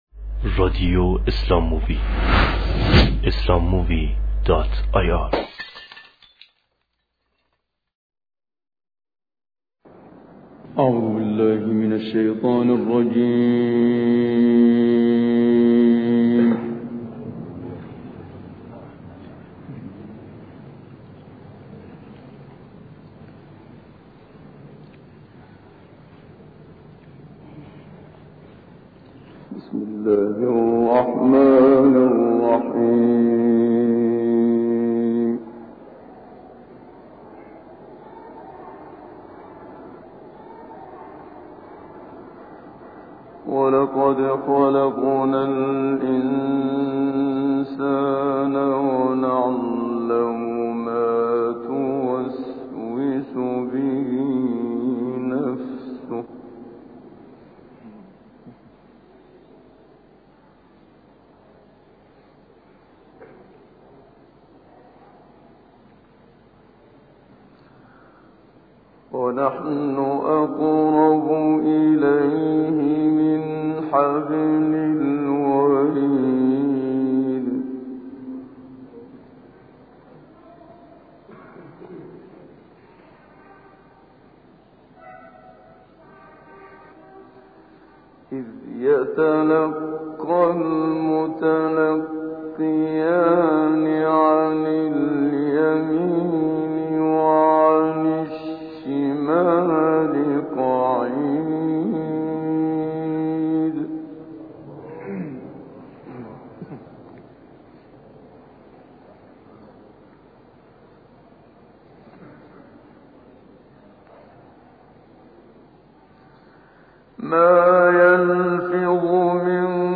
دانلود تلاوت سوره های قاف رحمن استاد محمد صدیق منشاوی – مجله نودیها
اشتراک گذاری : تلاوت زیبای سوره های مبارکه قاف و الرحمن از استاد محمد صدیق منشاوی به مدت سی و دو دقیقه با کیفیت ۱۶ کیلوبایت همان تلاوتی که برای تمرین قرائت توسط اساتید توصیه می شود.